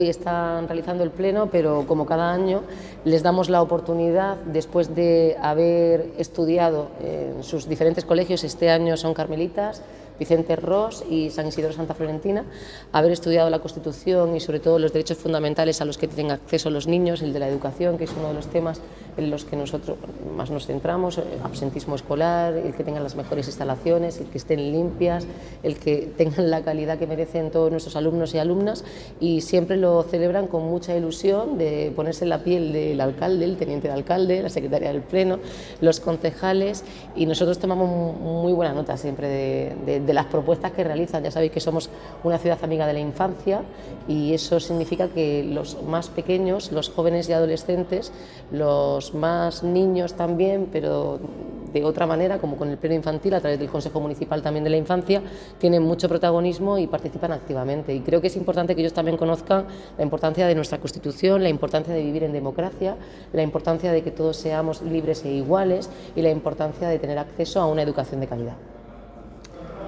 Enlace a Declaraciones de la alcaldesa, Noelia Arroyo.